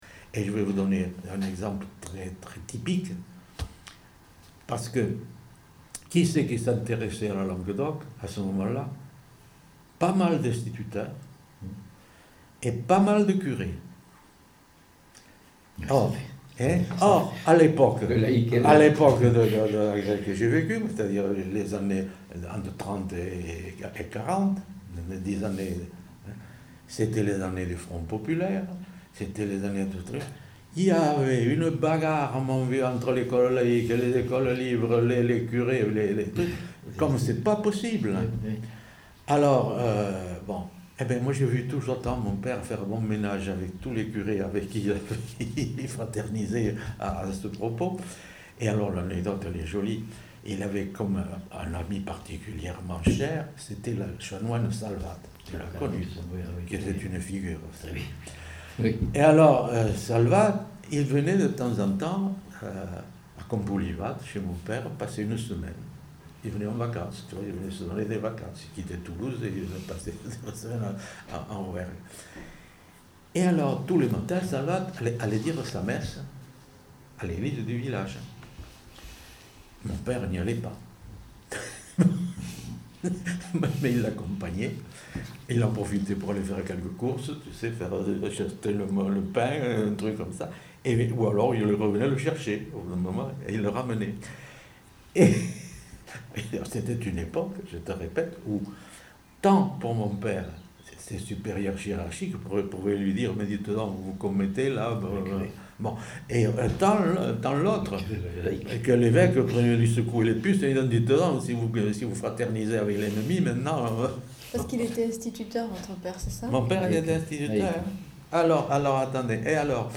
Lieu : Saint-Sauveur
Genre : témoignage thématique